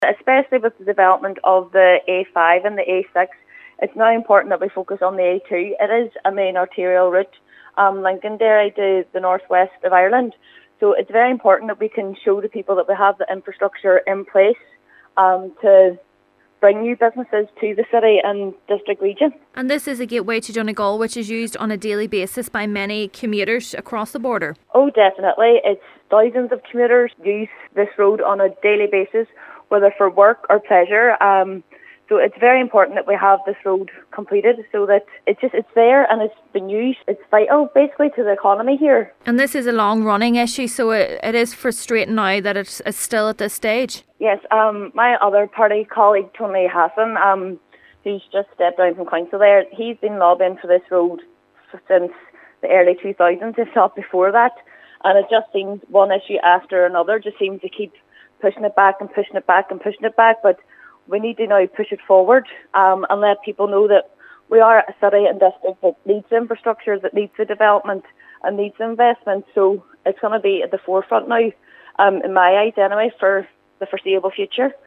Councillor Caoimhe McKnight who is bringing forward the motion, says it is now time, to showcase the North West as a region that needs to be invested in: